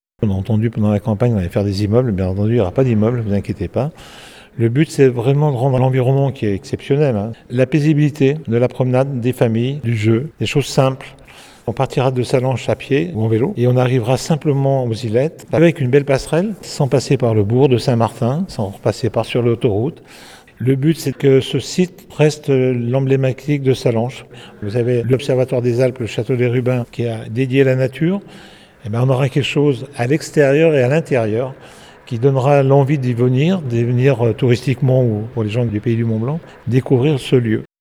Georges Morand est le maire de Sallanches.